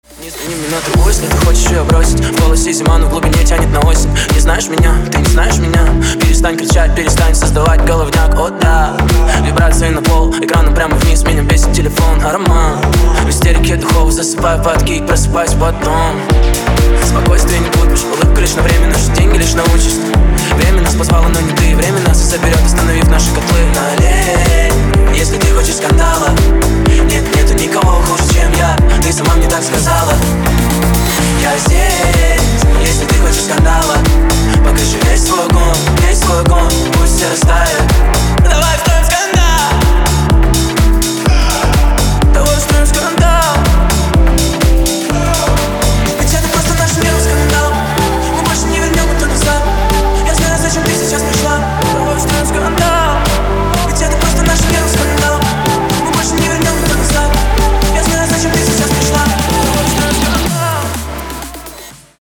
• Качество: 256, Stereo
мужской вокал
громкие
dance
Club House